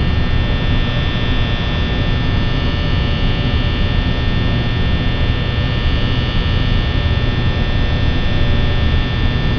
Buzz_Fan_Florescent_loop.wav